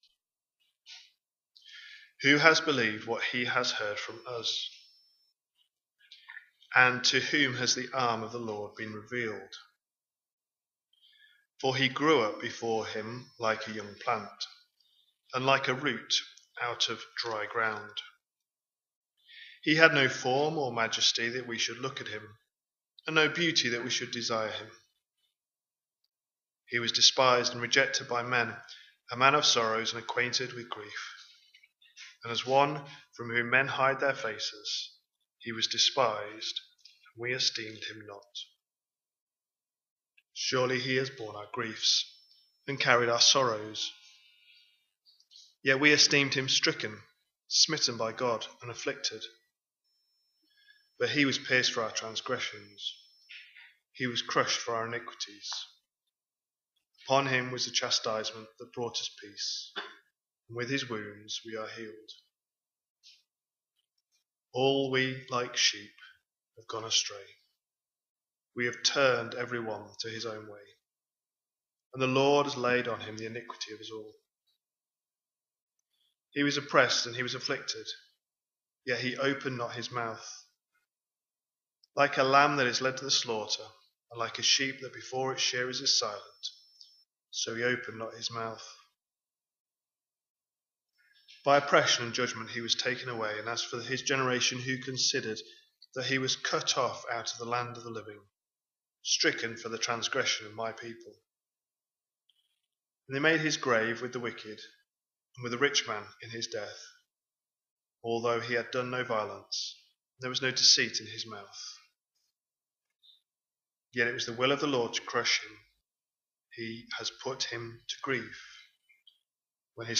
A sermon preached on 4th January, 2026, as part of our Mark 25/26 series.